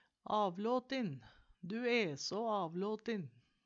Ordet vert og uttala utan v. Avlåten vert og bruka.